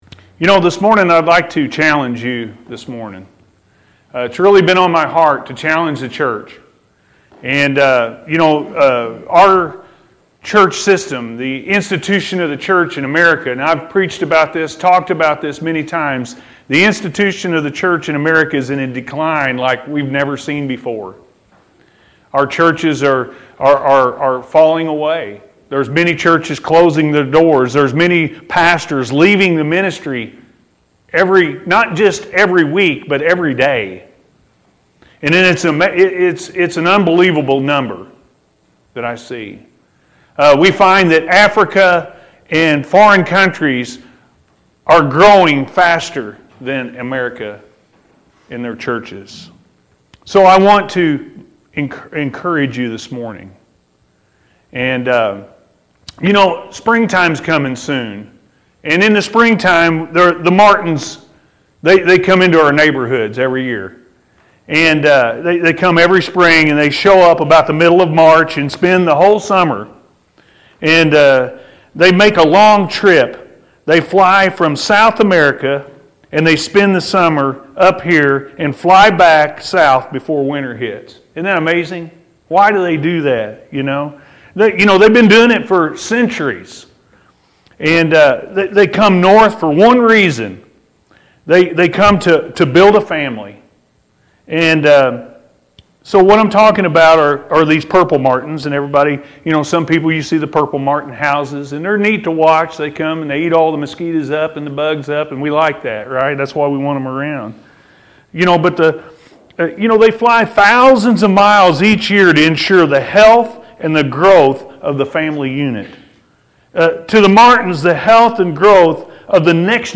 A Challenge To The Church-A.M. Service – Anna First Church of the Nazarene